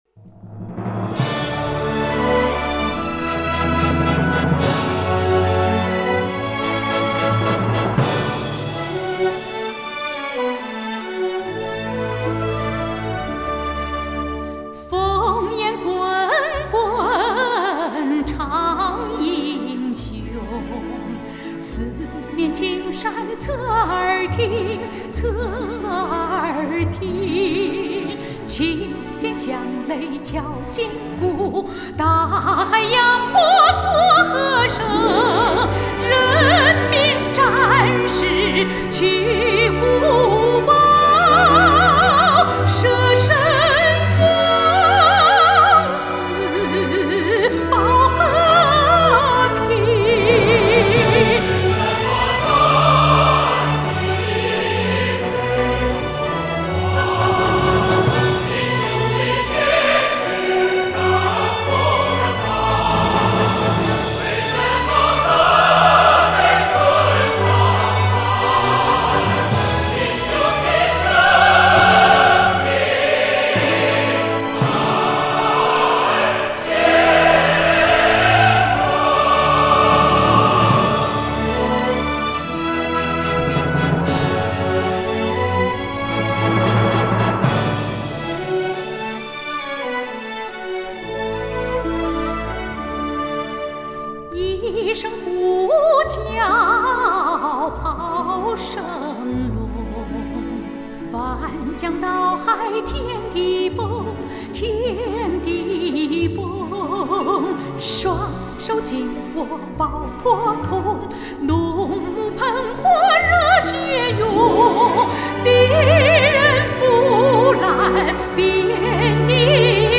插曲